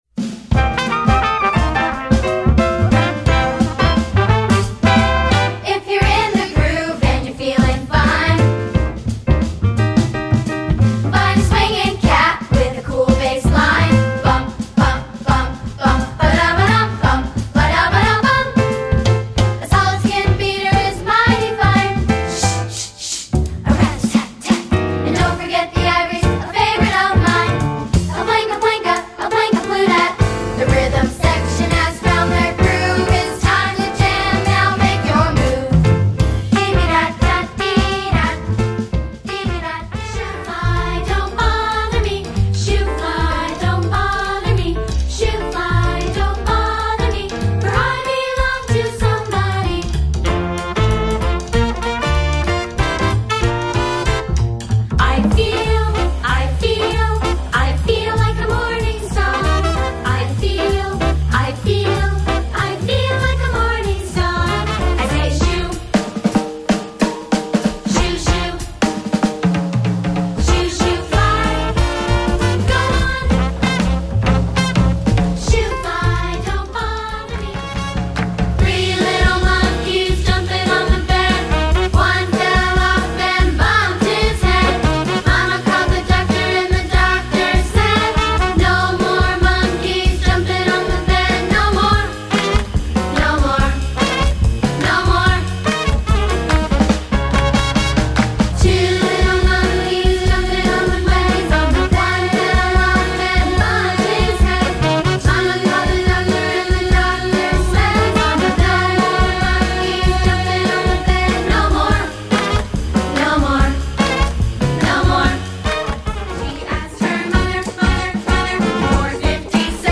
Recueil pour Chant/vocal/choeur - Voix